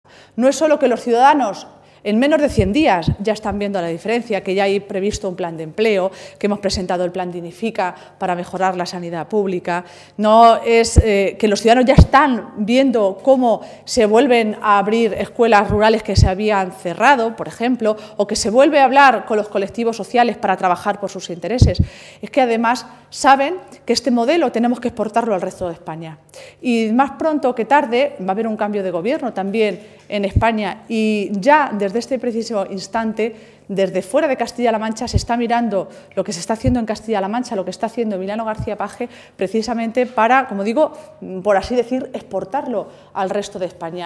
Maestre ha realizado estas declaraciones en un receso de la reunión del Comité regional socialista, el primero que se celebra tras las elecciones autonómicas y municipales del pasado mes de mayo.